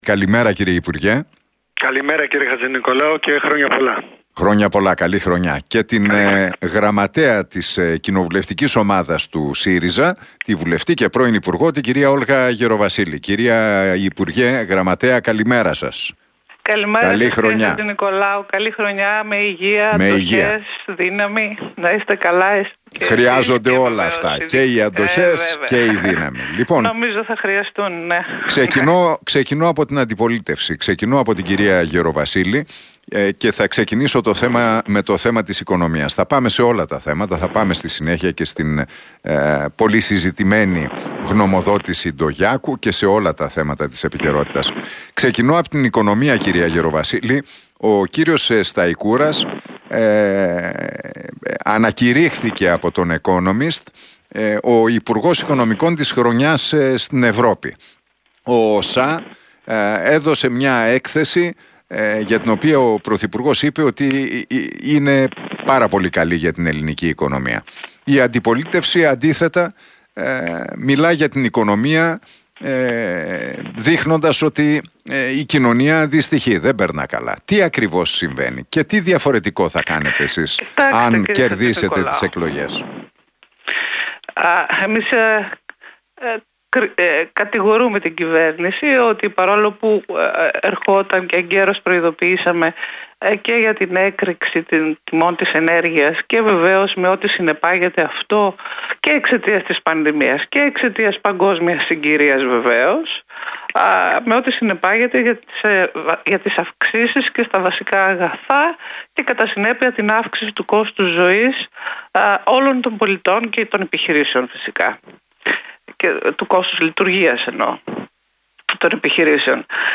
Debate Θεοχάρη - Γεροβασίλη στον Realfm 97,8 για την γνωμοδότηση Ντογιάκου και την οικονομία
Τα ξίφη τους διασταύρωσαν στον αέρα του Realfm 97,8 και στην εκπομπή του Νίκου Χατζηνικολάου, ο κοινοβουλευτικός εκπρόσωπος της ΝΔ, Χάρης Θεοχάρης και η Γραμματέας της ΚΟ του ΣΥΡΙΖΑ, Όλγα Γεροβασίλη.